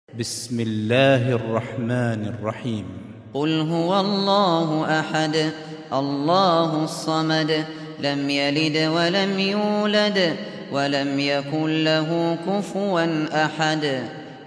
سُورَةُ الإِخۡلَاصِ بصوت الشيخ ابو بكر الشاطري